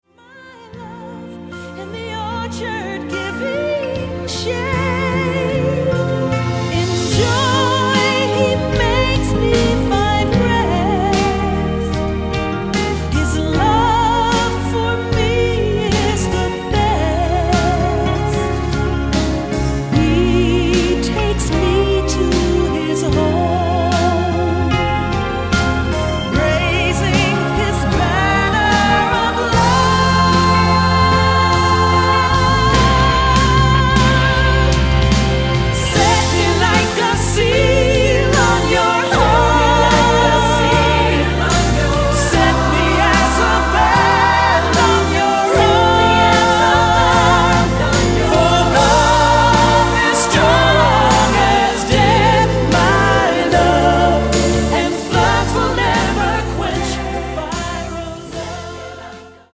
Voicing: SATB; Solo; Assembly